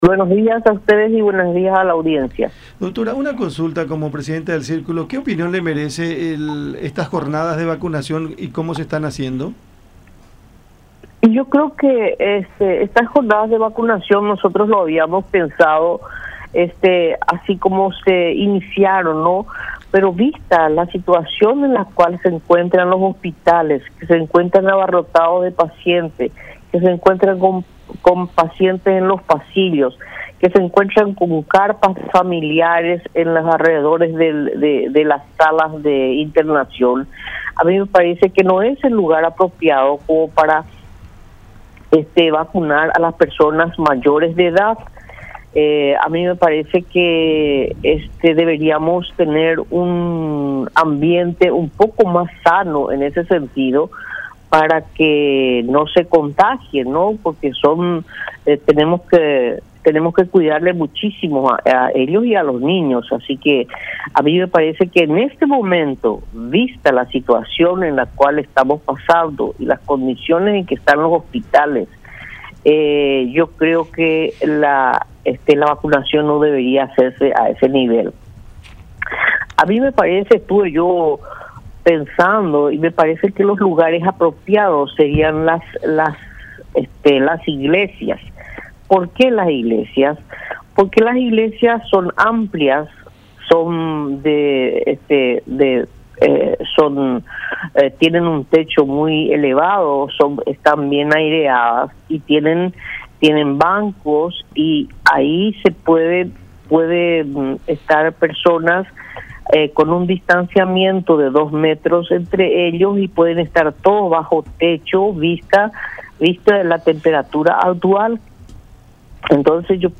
en charla con La Unión